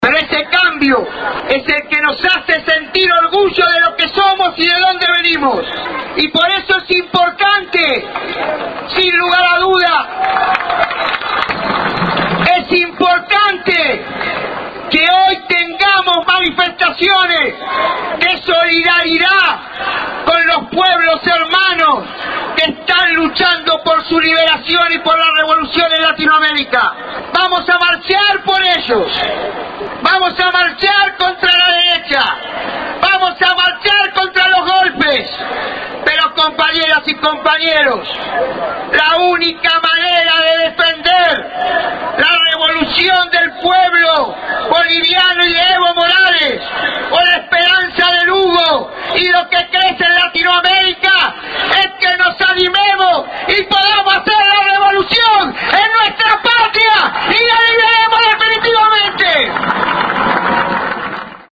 Nota con audio de Víctor de Gennaro La hora de animarse El 30 de julio de 2009 El dirigente de la CTA participó del cierre de la Constituyente Social en San Salvador de Jujuy y propuso mantener firme la solidaridad con los pueblos del continente que luchan por su liberación.